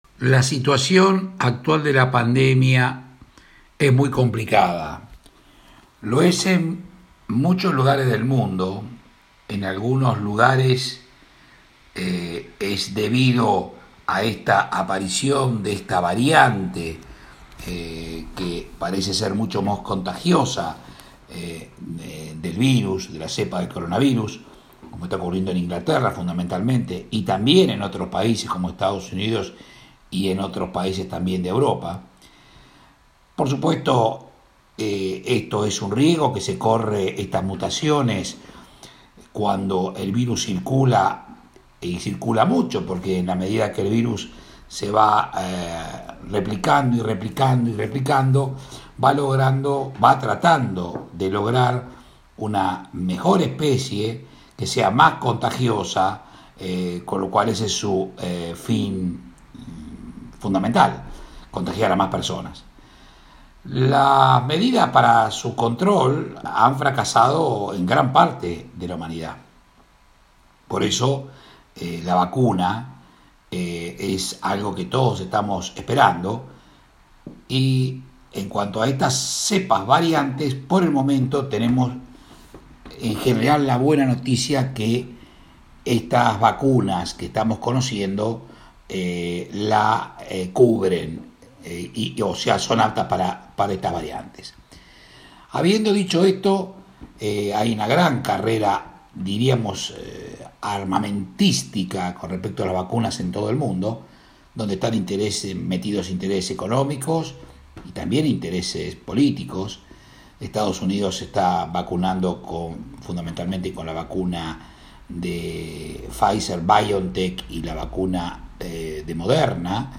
Alejandro Ferro, infectólogo y ex Secretario de Salud, aseguró que la situación actual de la pandemia en Mar del Plata es muy compleja, como lo es en diversos lugares del mundo a causa de la variante de Covid-19 que se registró en el último tiempo y parece ser más contagiosa, hecho que afecta directamente a Inglaterra, EE.UU y países de Europa.
El infectólogo habló sobre vacunas que contienen código genético para crear la proteína “Spike” y también sobre las vacunas de virus inactivados, puntualmente se refirió a las que se están aplicando en la Argentina. Escucha el informe que realizó para MdpYa: